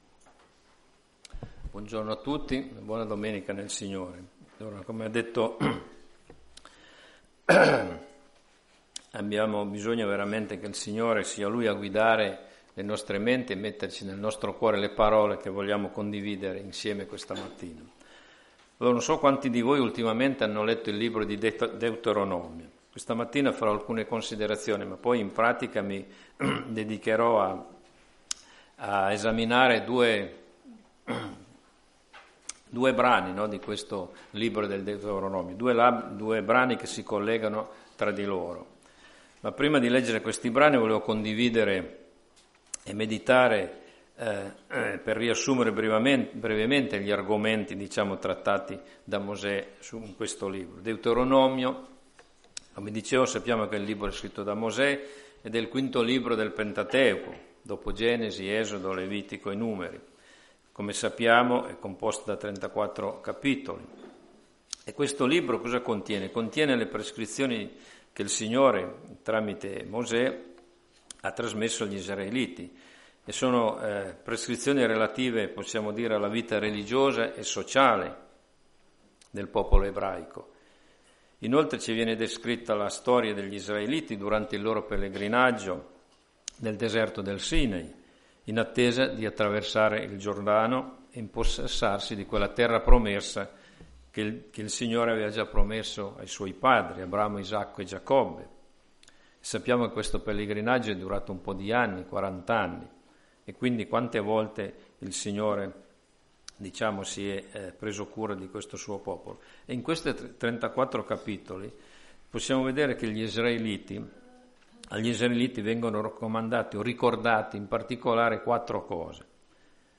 Chiesa Cristiana Evangelica - Via Di Vittorio, 14 Modena
Predicazioni